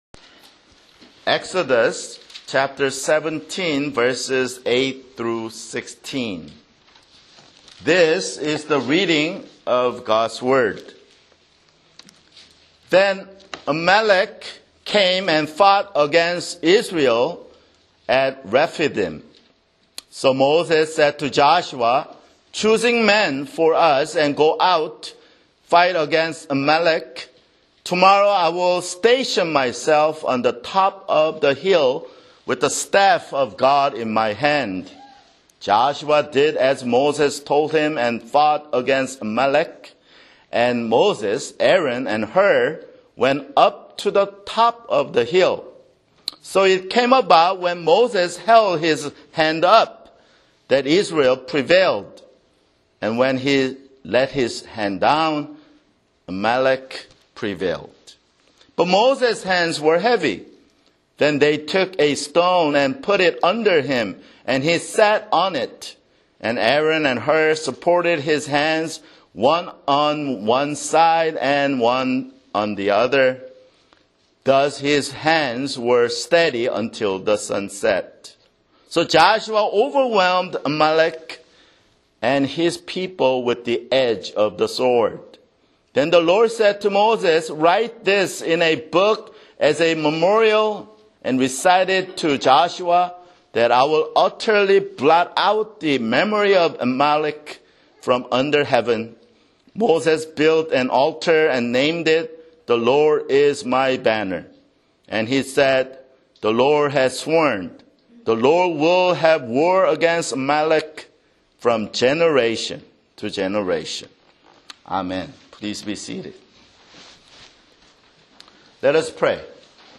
[Sermon] Exodus 17:8-16
Download MP3 (Right click on the link and select "Save Link As") Labels: Sermon - Lord's Supper